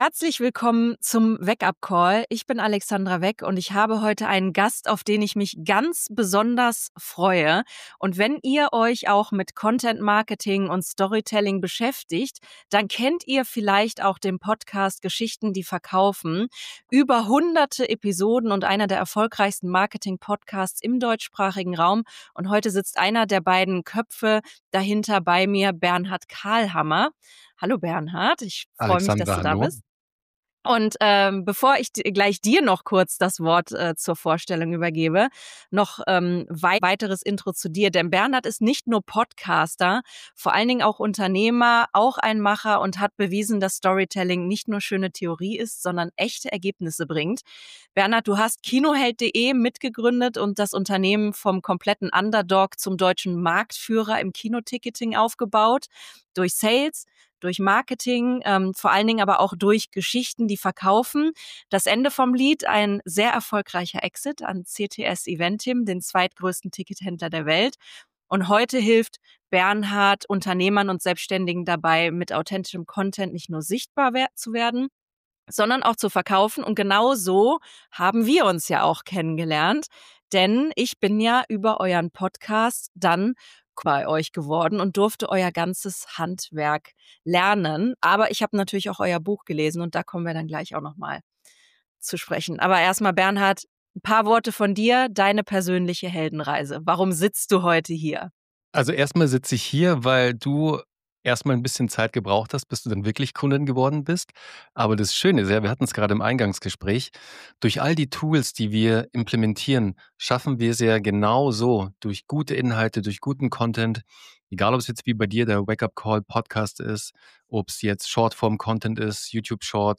Ein Gespräch über Relevanz statt Reichweite, Substanz statt Show – und warum du dein eigenes Medienhaus werden solltest, bevor es deine Wettbewerber tun.